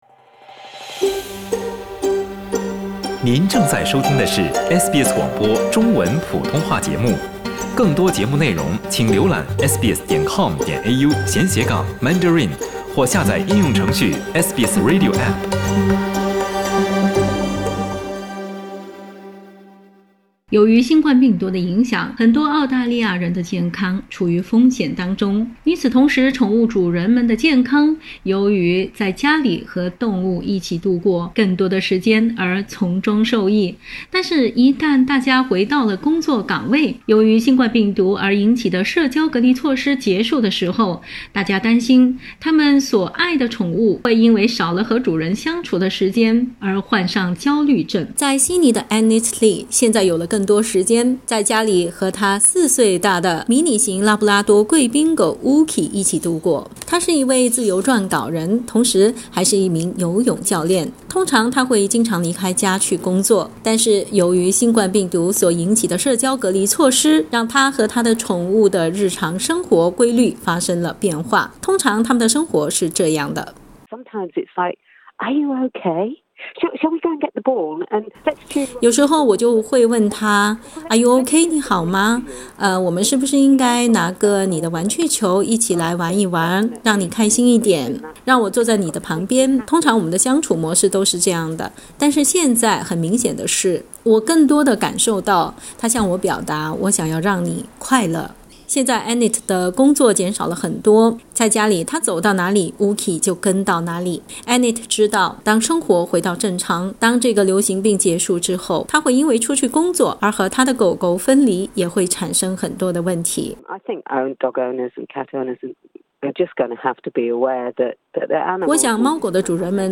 【COVID-19报道】宠物在疫情过后可能面临焦虑症 主人应如何及早应对？